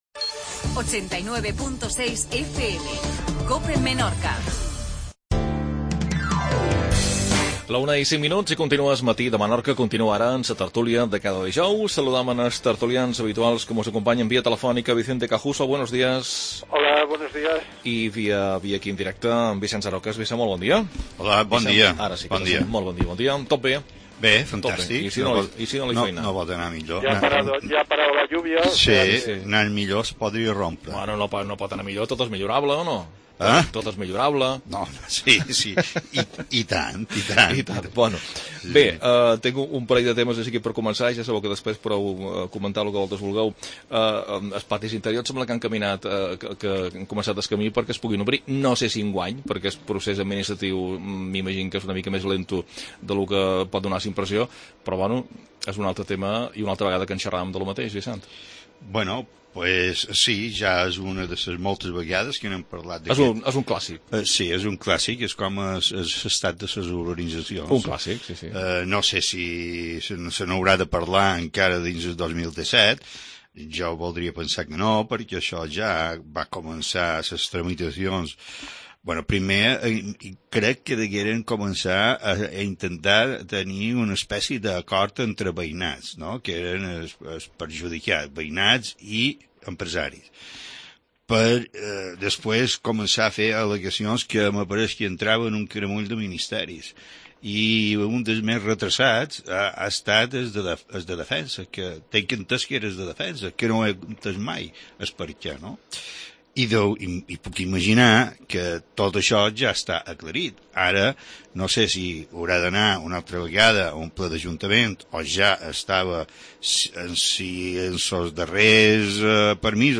Tertulia.